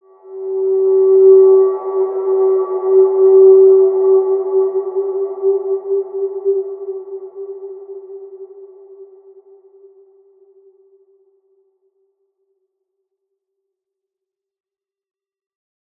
Large-Space-G4-p.wav